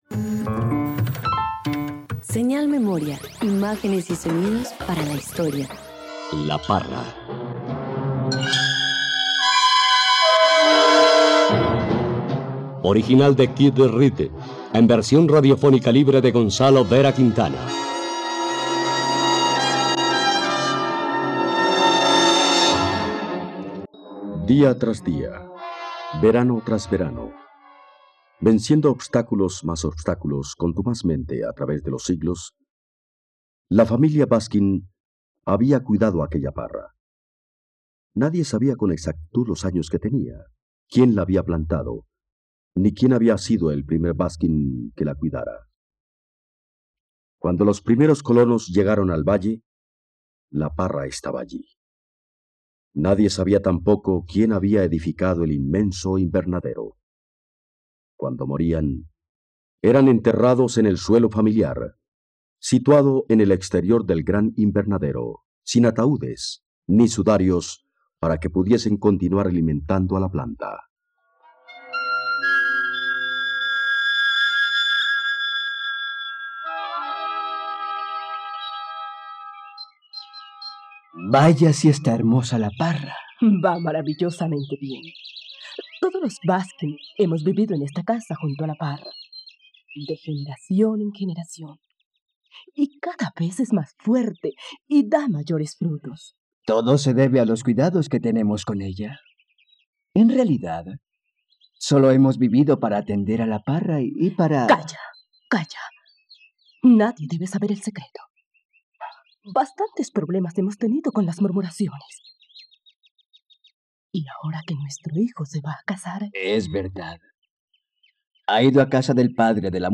La parra - Radioteatro dominical | RTVCPlay